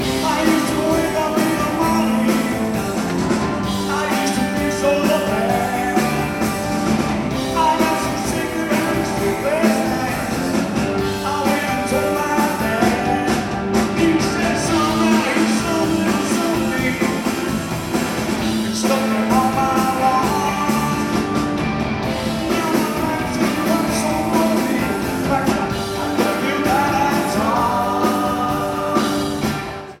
Format/Rating/Source: CD - C - Audience
Comments: Good audience recording